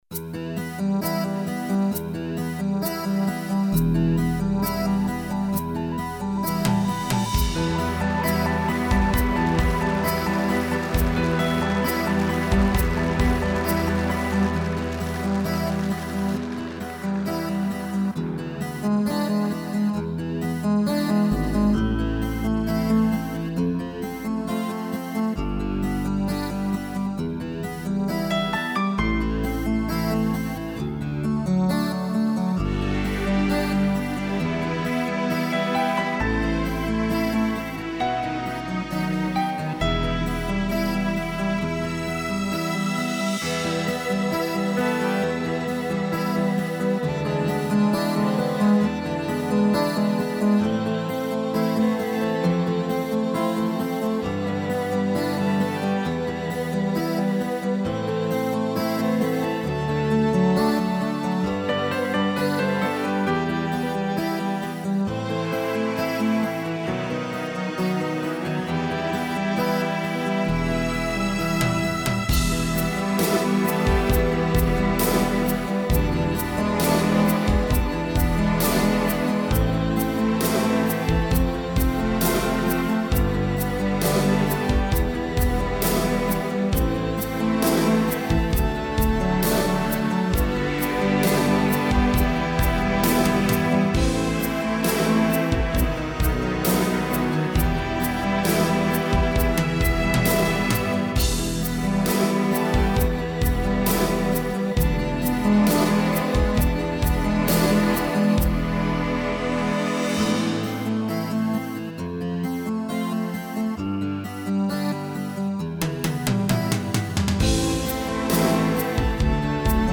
минусовка версия 35630